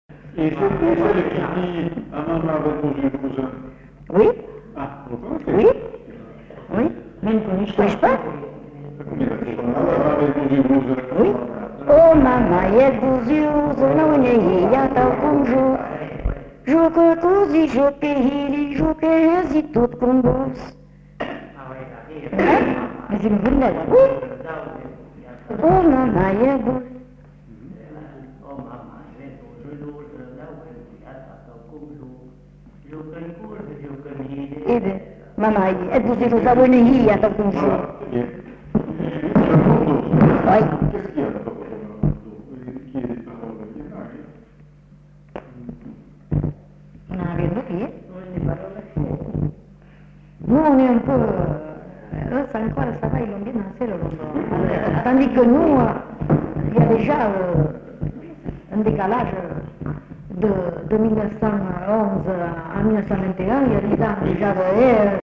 Lieu : Cazalis
Genre : chant
Effectif : 1
Type de voix : voix de femme
Production du son : chanté
Danse : rondeau